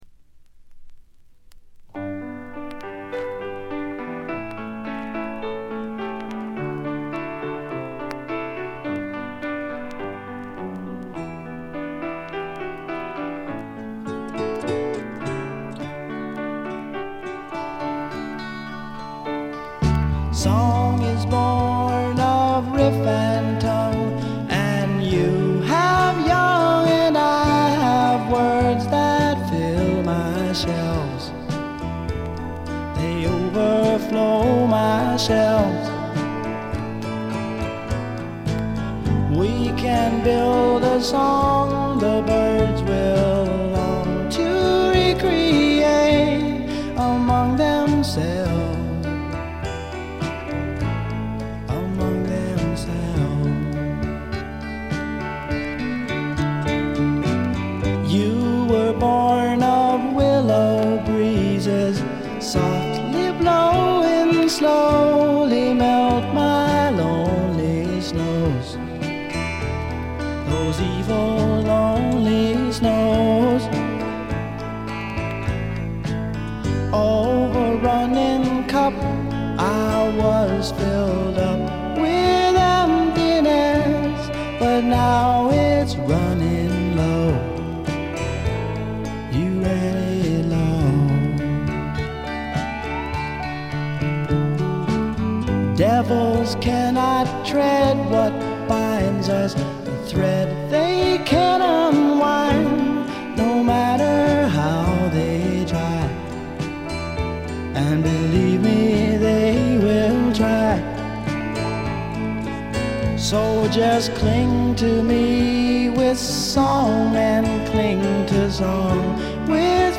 A1序盤のプツ音2回、B2冒頭のプツ音1回あたりが気になった程度です。
試聴曲は現品からの取り込み音源です。